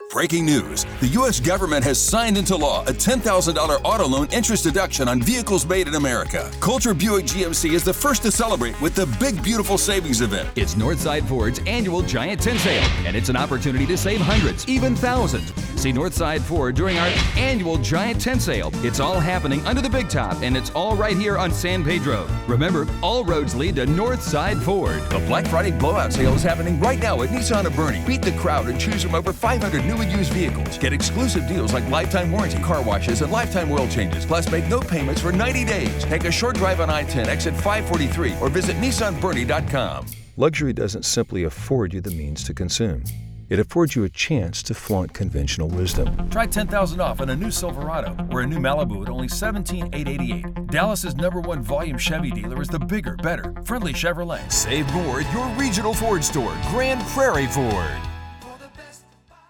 Powerful, deep male Voiceover with over 27 years experience in all kinds of projects
Automotive
Texan Southwestern